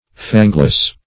Fangless \Fang"less\
fangless.mp3